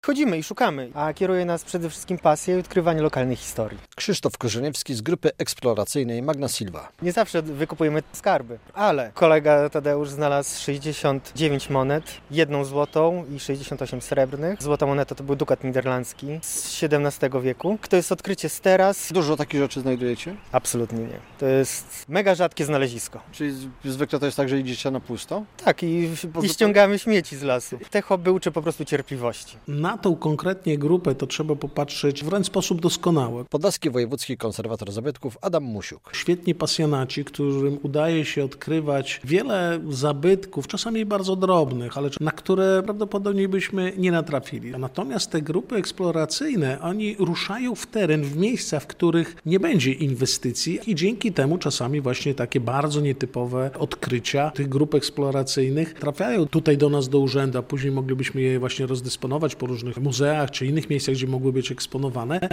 Grupa Magna Silva to doskonały przykład wzorowego działania - zauważa wojewódzki konserwator zabytków Adam Musiuk.